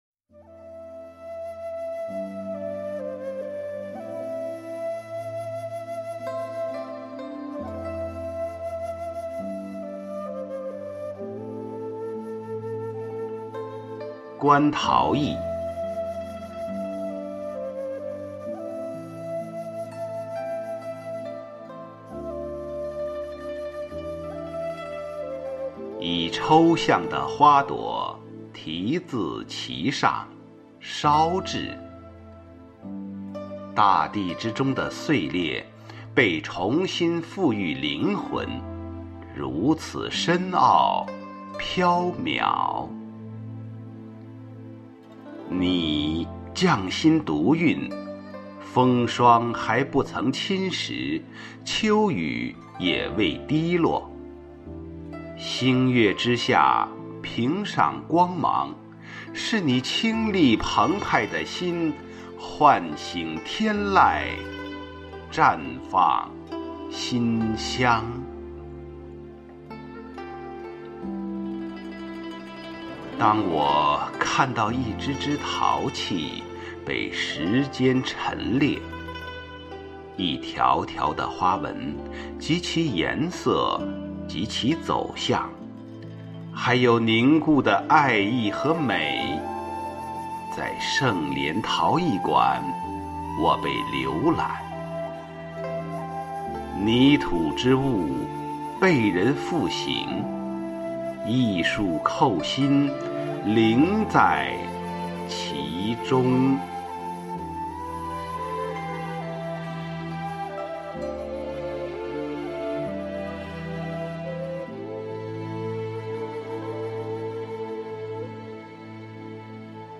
誦讀